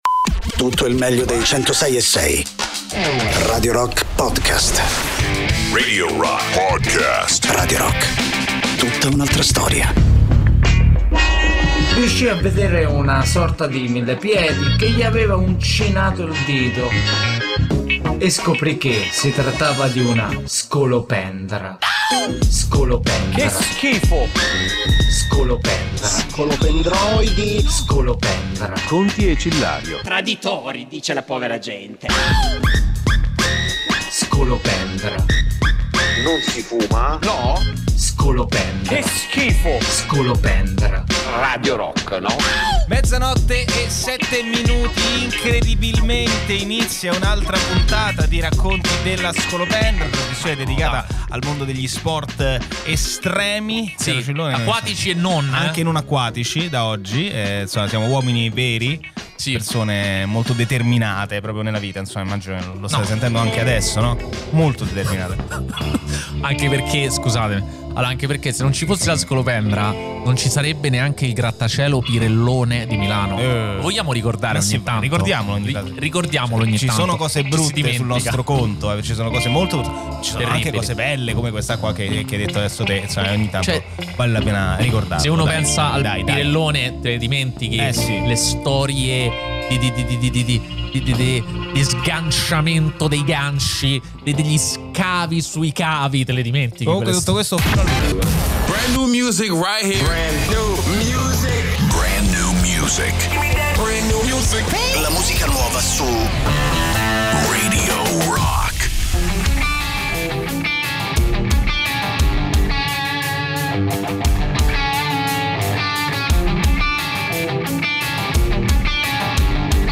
in diretta Sabato e Domenica dalle 15 alle 18